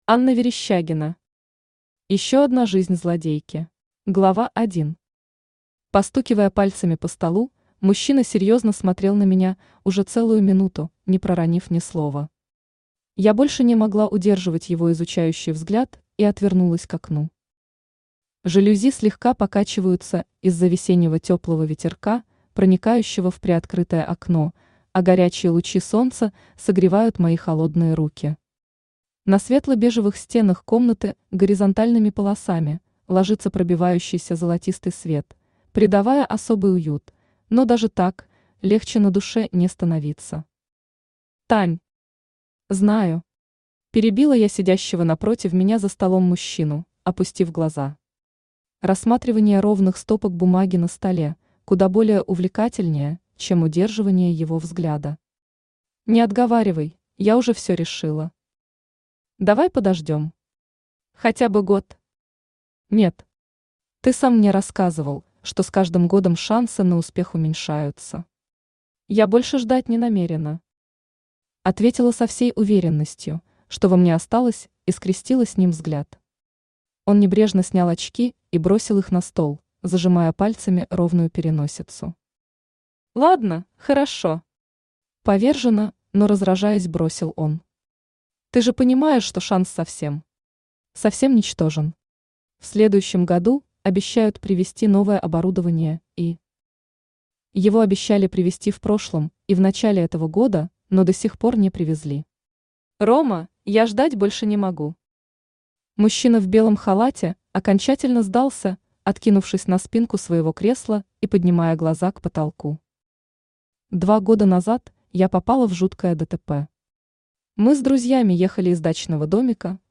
Аудиокнига Ещё одна жизнь злодейки | Библиотека аудиокниг
Aудиокнига Ещё одна жизнь злодейки Автор Анна Верещагина Читает аудиокнигу Авточтец ЛитРес.